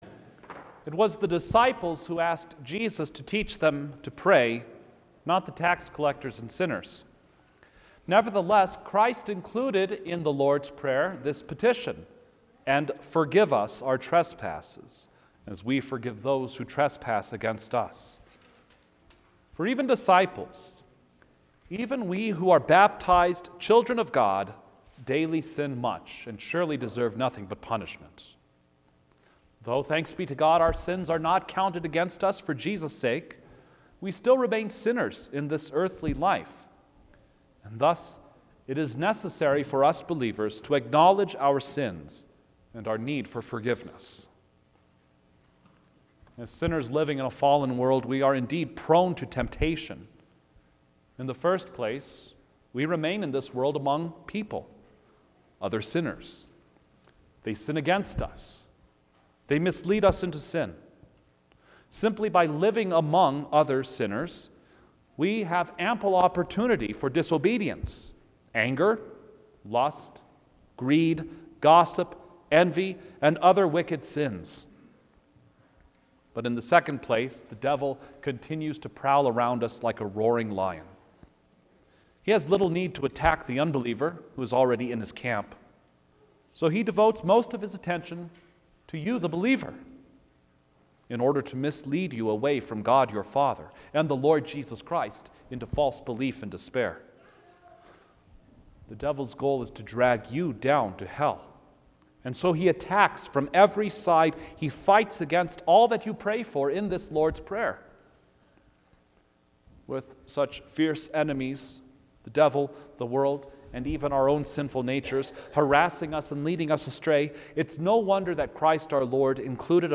Lenten Midweek Service Five